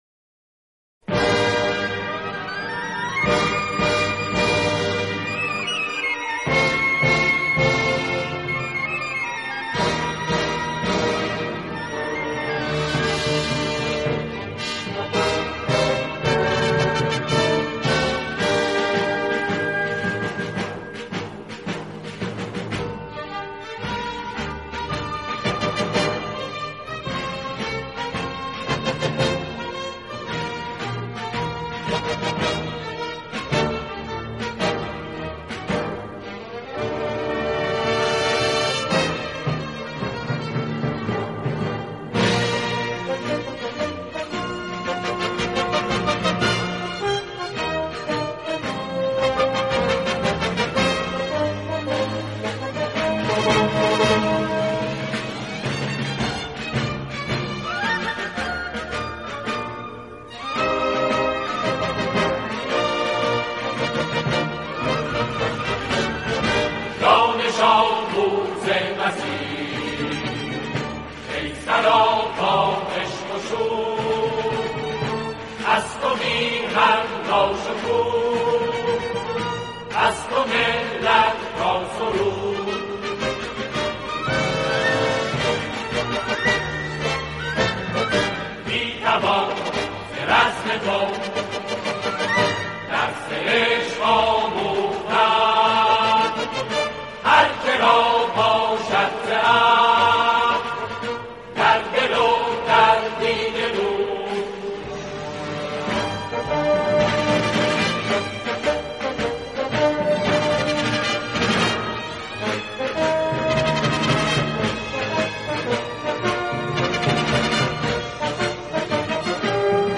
به همین مناسبت سرود
توسط یک گروه کُر اجرا شده است.